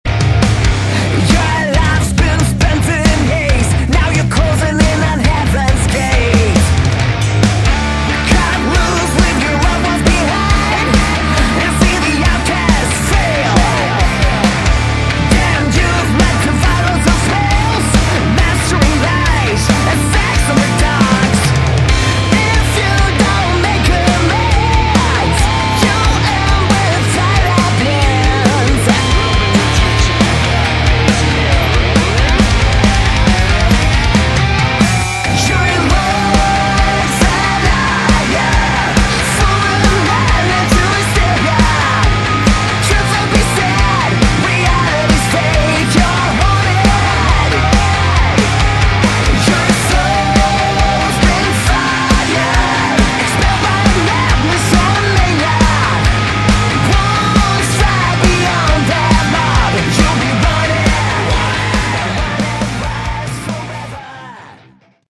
Category: Melodic Metal
Guitars, Vocals
Bass
Drums